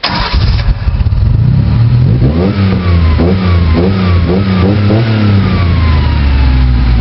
Index of /server/sound/vehicles/lwcars/uaz_452
startup.wav